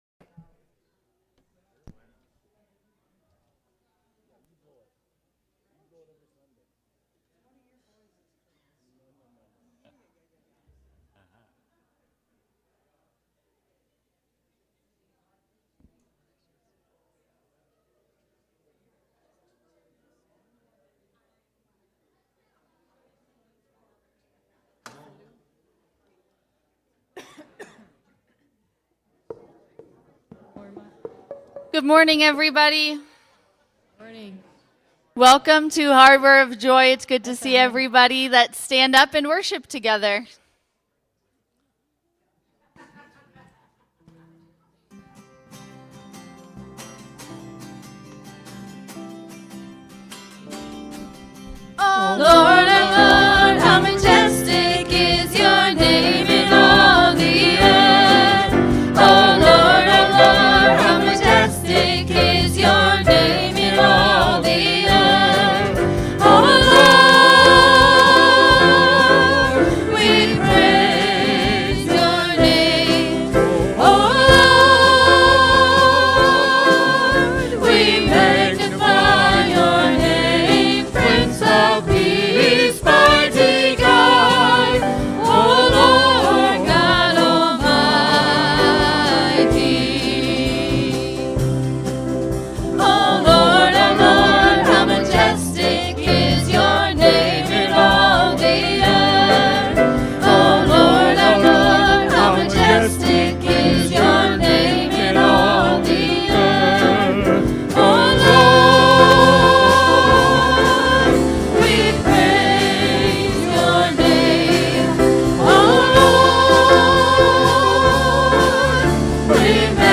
Worship-June-1-2025-Voice-Only.mp3